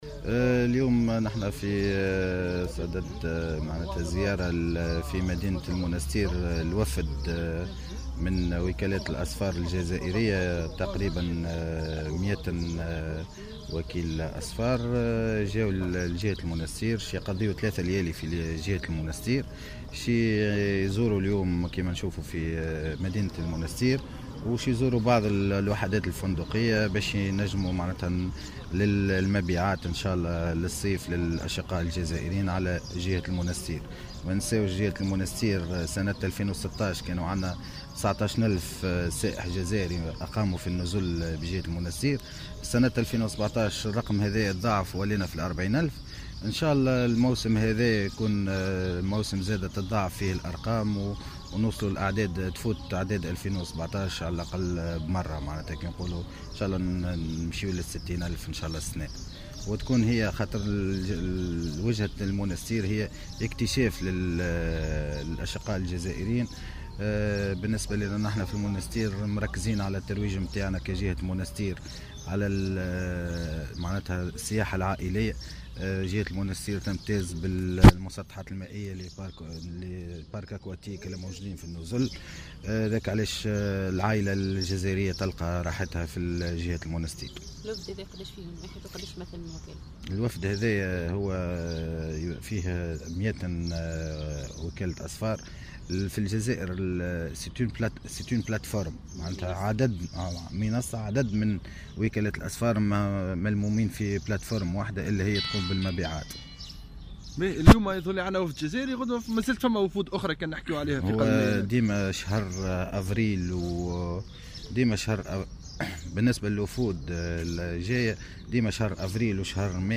نشرة أخبار السابعة مساءً ليوم الخميس 5 أفريل 2018